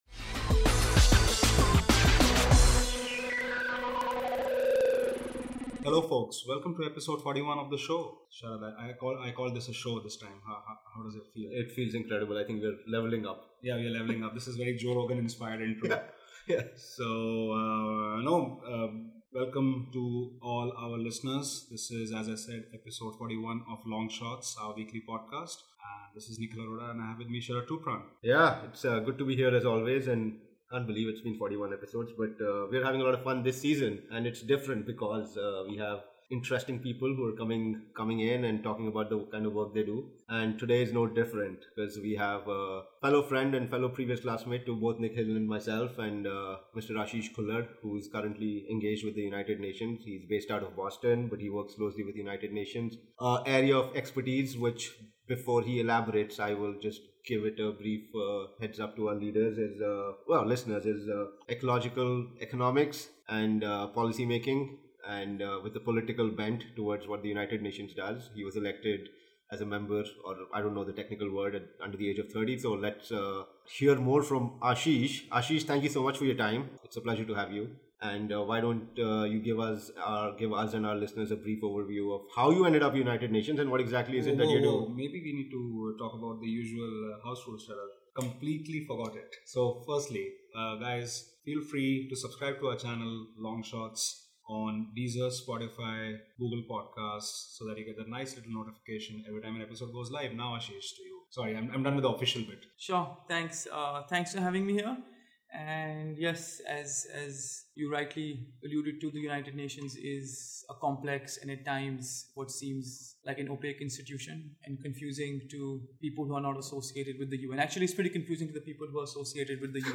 In this week's cross-dimensional conversation, we touch upon (among other things):